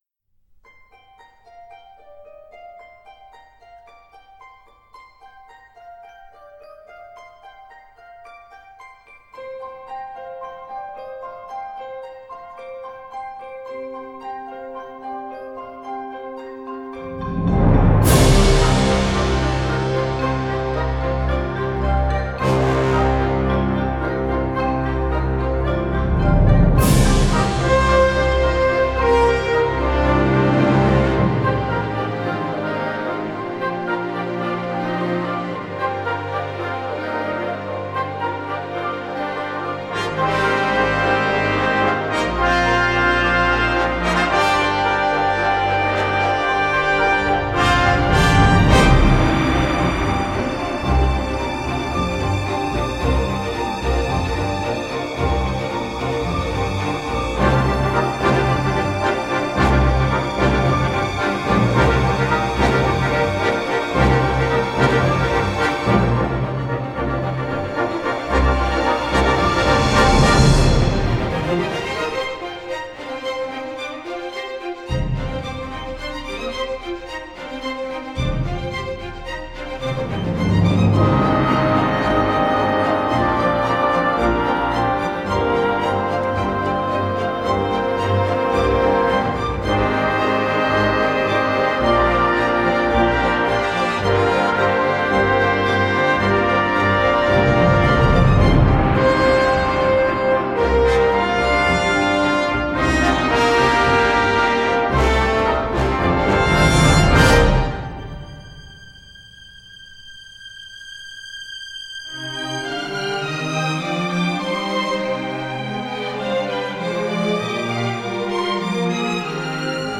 Жанр: Soundtrack